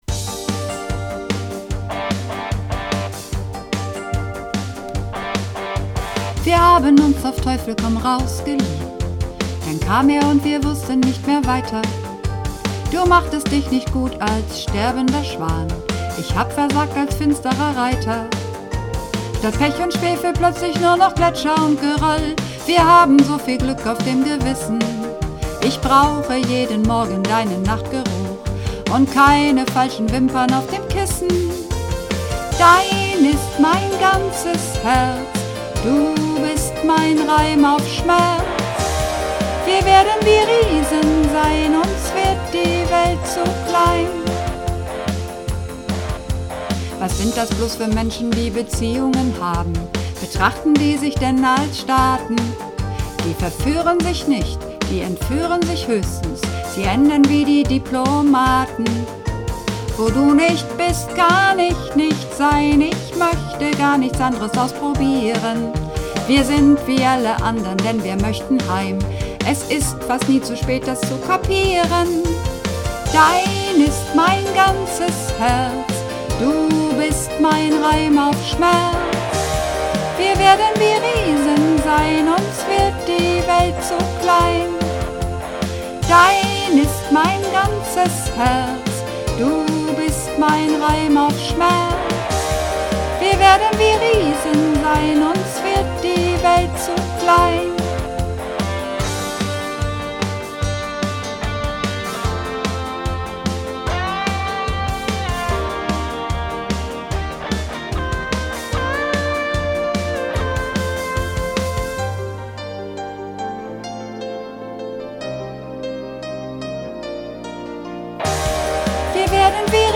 Übungsaufnahmen - Dein ist mein ganzes Herz
Dein ist mein ganzes Herz (Sopran)
Dein_ist_mein_ganzes_Herz__3_Sopran.mp3